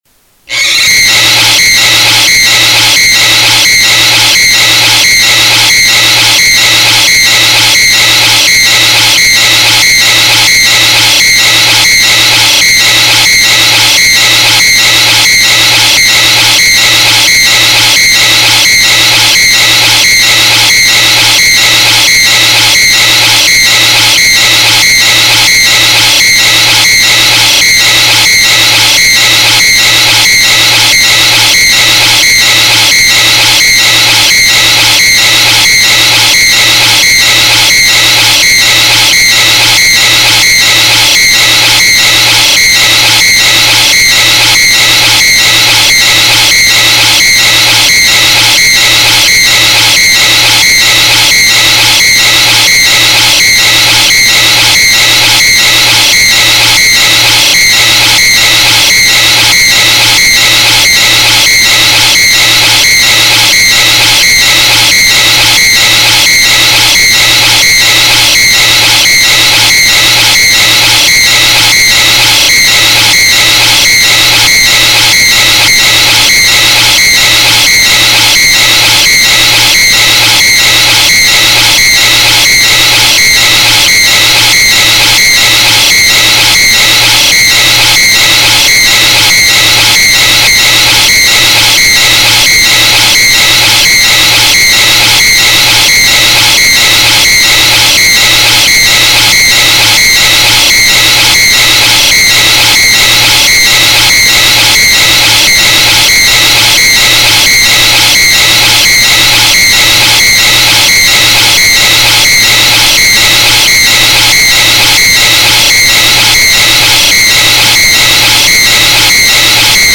Звуки скримера, неожиданности
Сделайте потише